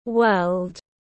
World /wɜːld/